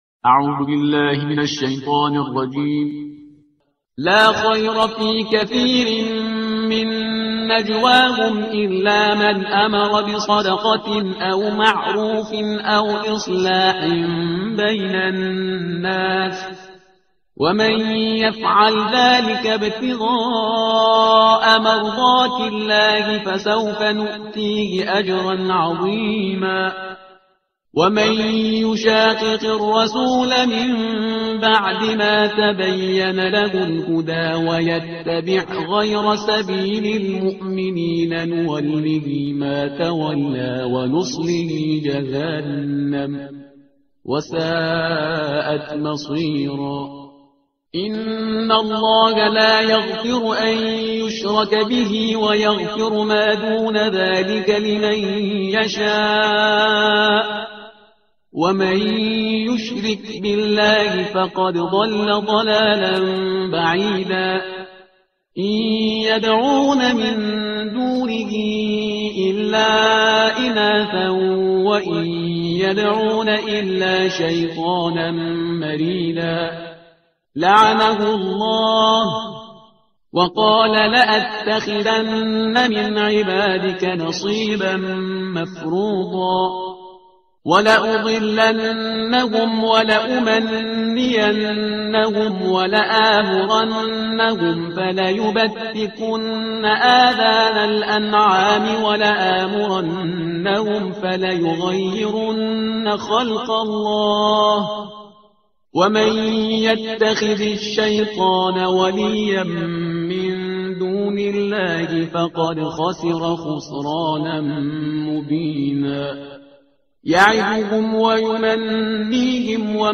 ترتیل صفحه 97 قرآن با صدای شهریار پرهیزگار